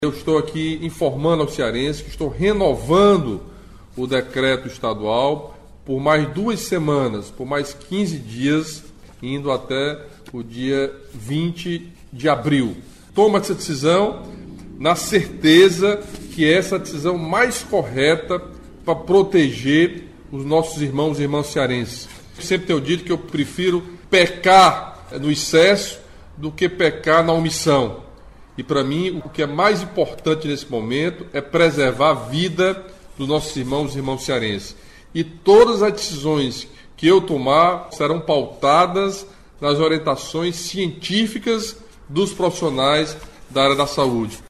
A informação foi repassada pelo governador Camilo Santana neste sábado (4), em transmissão ao vivo por suas redes sociais, momento em que o chefe do Executivo estadual explicou que a medida foi tomada levando em consideração questões técnicas e científicas.